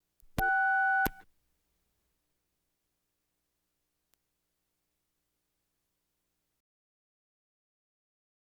Telephone Dial Button Tone Internal Sound Effect
Download a high-quality telephone dial button tone internal sound effect.
telephone-dial-button-tone-internal-6.wav